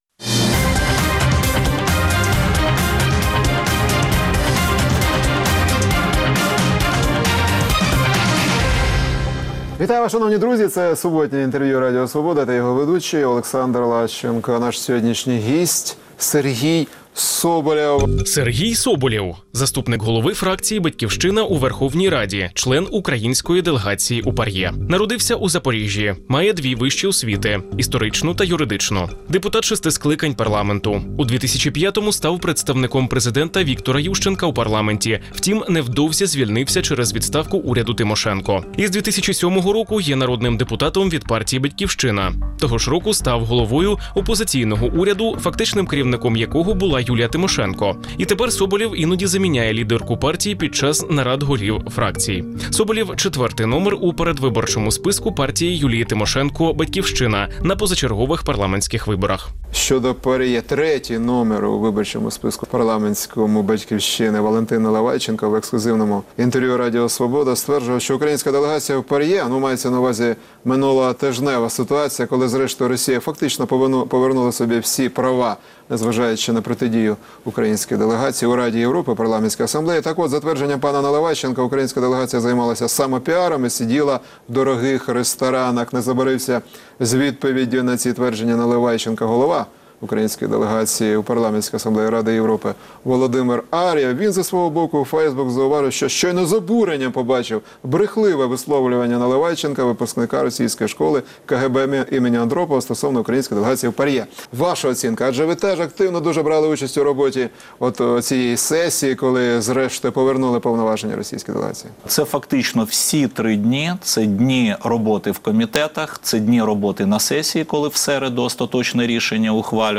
Суботнє інтерв’ю | Сергій Соболєв, заступник керівника партії «Батьківщина»
Суботнє інтвер’ю - розмова про актуальні проблеми тижня. Гість відповідає, в першу чергу, на запитання друзів Радіо Свобода у Фейсбуці